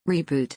reboot.mp3